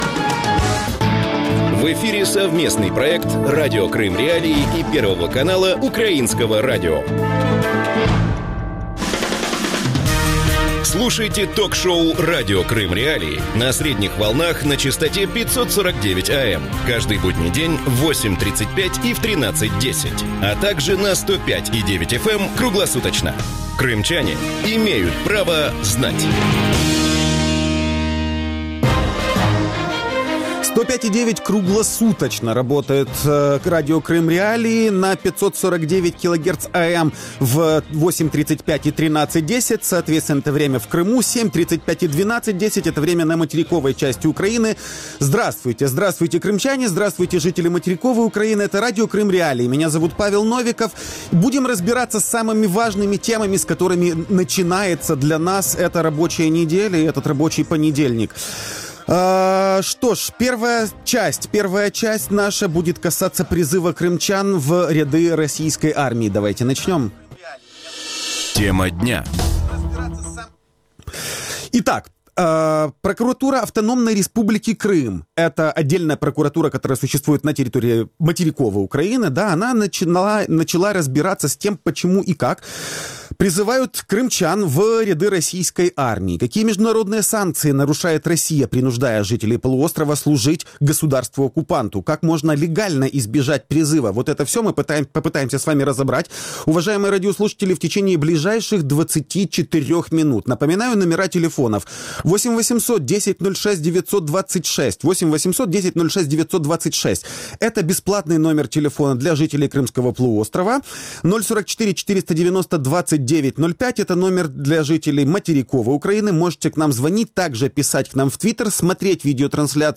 Как можно легально избежать призыва в российскую армию? Гости эфира